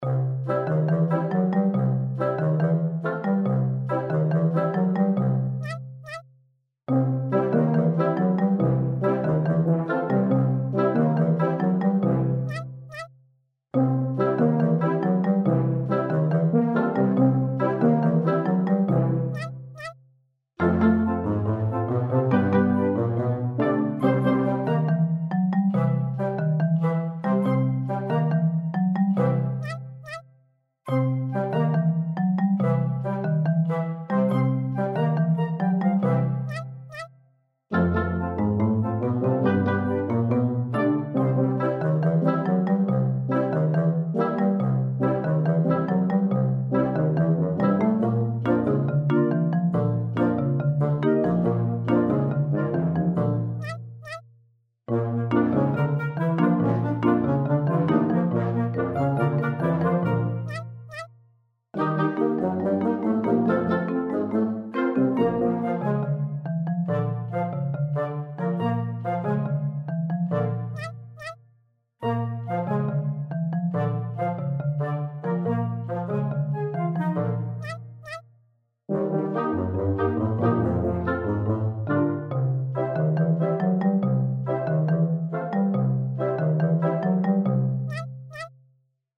Cinematic
HumorousCute